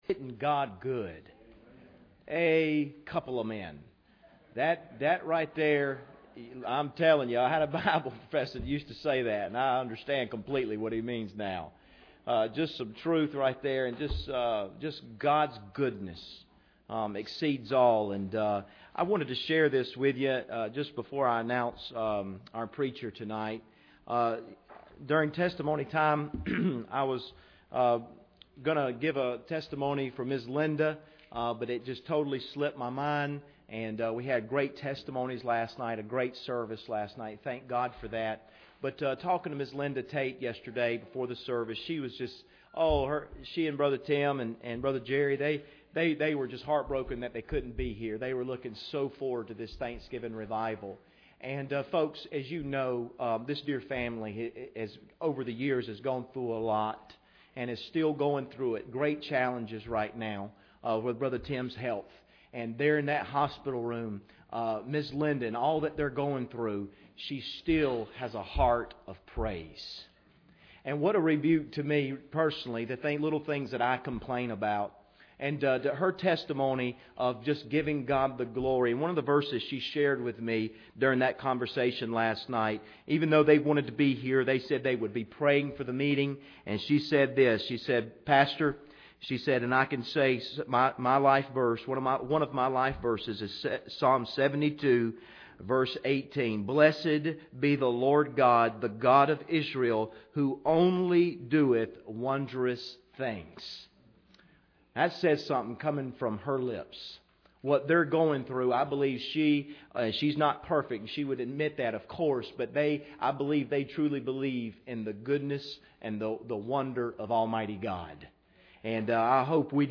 Passage: 1 Kings 17:1-2 Service Type: Revival Service